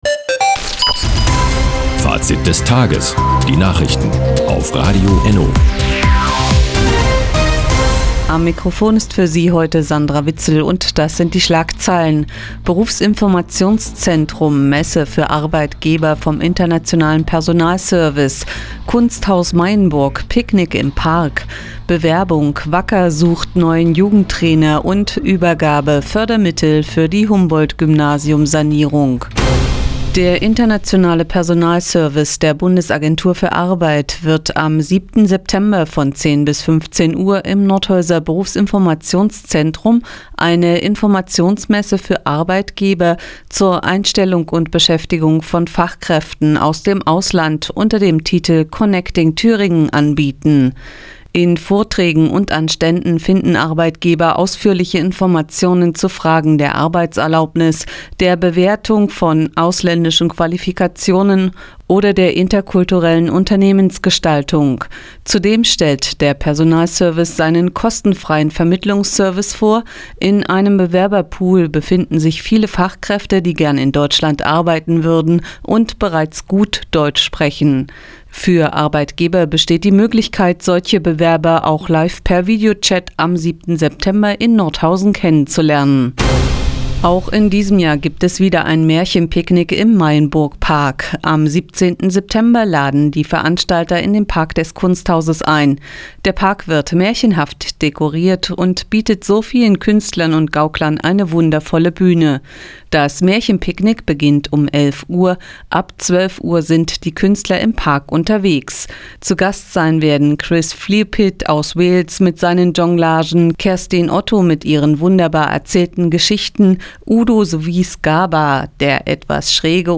Die tägliche Nachrichtensendung ist jetzt hier zu hören...